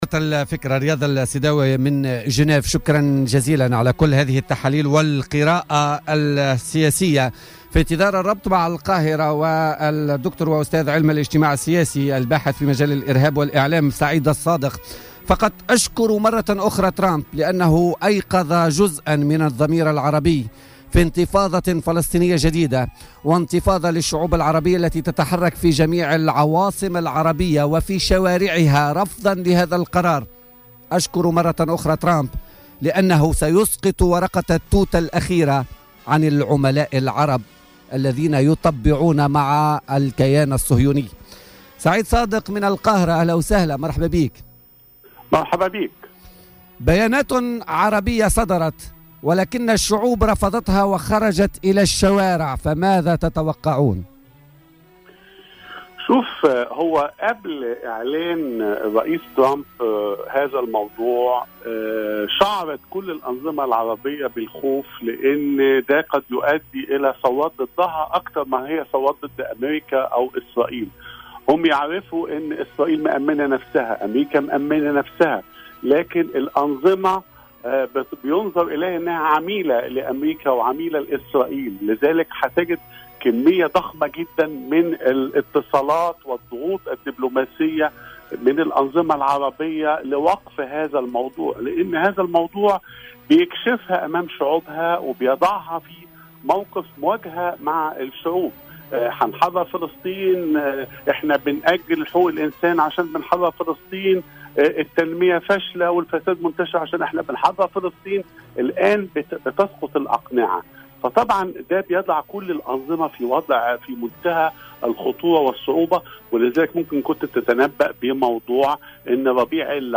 في اتصال هاتفي من القاهرة